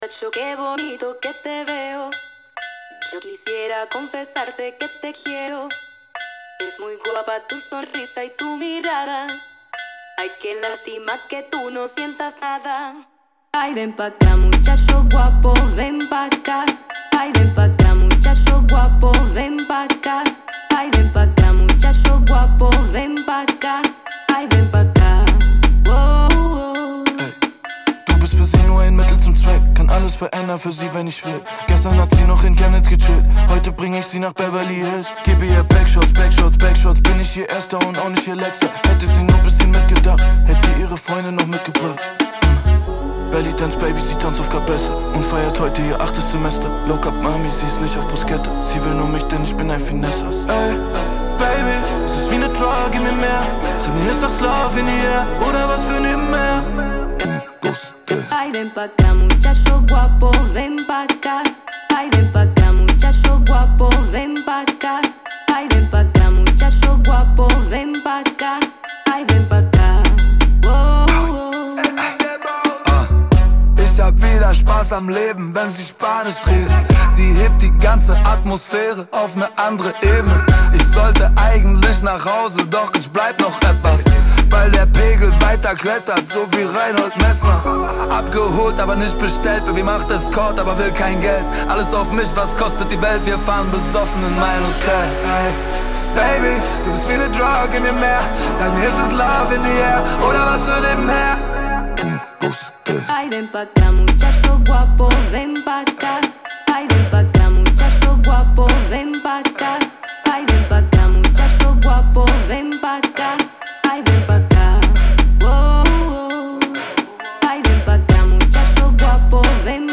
HipHop GER